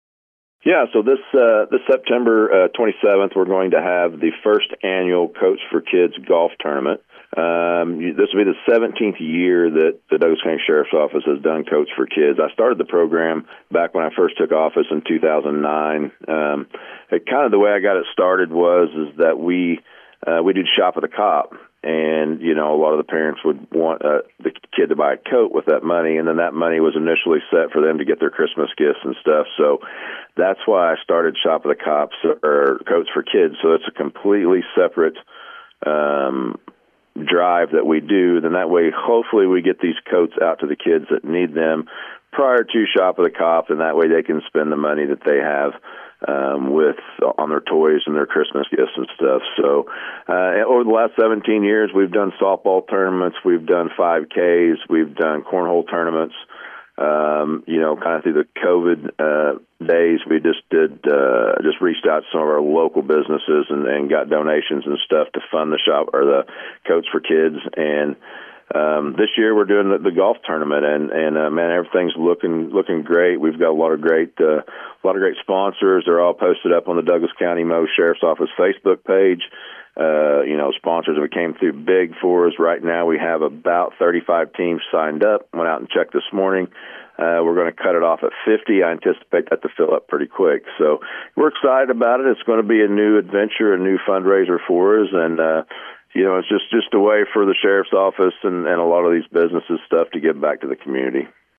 Sheriff Chris Degase talked to us about the program, and you can listen to his statements below.